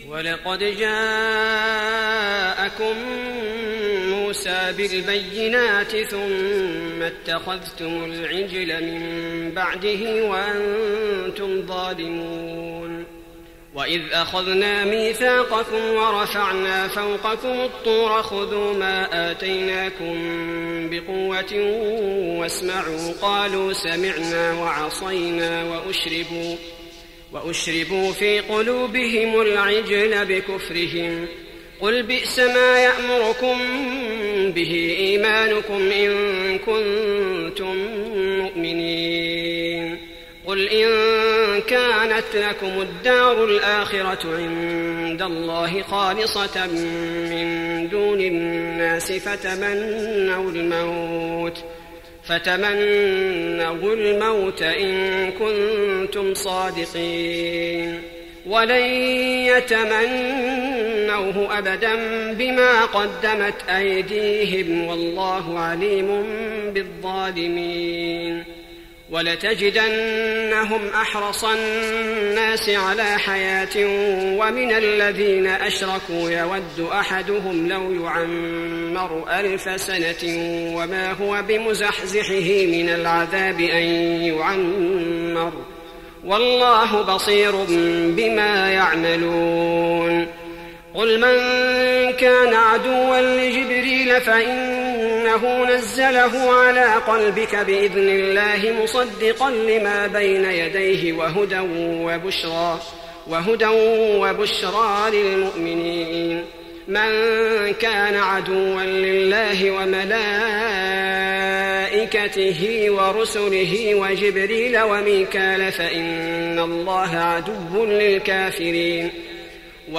تهجد رمضان 1415هـ من سورة البقرة (92-157) Tahajjud night Ramadan 1415H from Surah Al-Baqara > تراويح الحرم النبوي عام 1415 🕌 > التراويح - تلاوات الحرمين